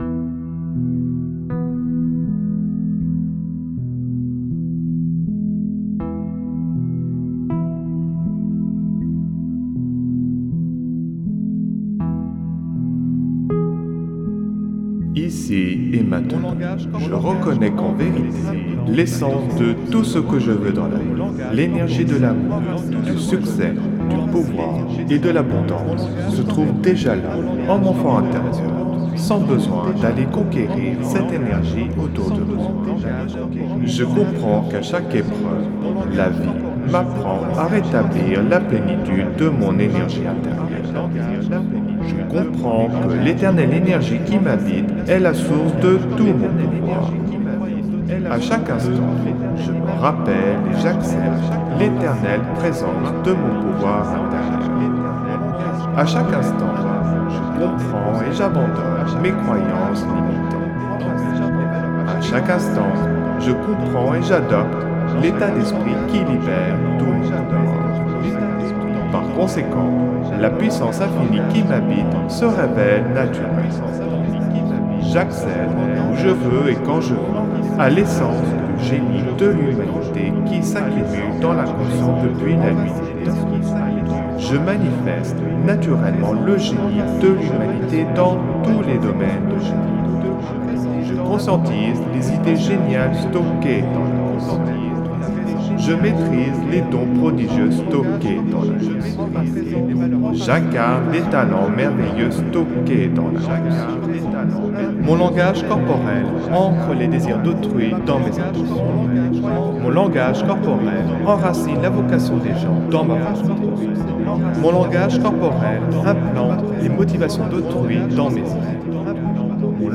Alliage ingénieux de sons et fréquences curatives, très bénéfiques pour le cerveau.
Pures ondes gamma intenses 61,94 Hz de qualité supérieure. Puissant effet 3D subliminal écho-guidé.
SAMPLE-Persuasion-2-echo-1.mp3